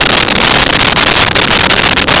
Sfx Pod Flamethrower Loop
sfx_pod_flamethrower_loop.wav